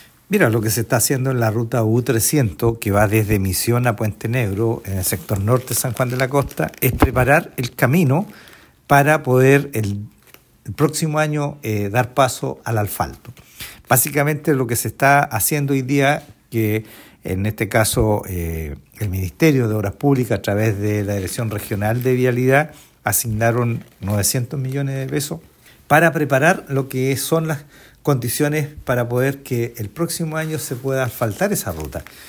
En este sentido, el alcalde de San Juan de la Costa, Bernardo Candia, comentó que lo que se está haciendo en la ruta U-300 que va desde Misión a Puente Negro en el sector norte de la comuna, es preparar el camino para poder el próximo año dar paso al asfalto.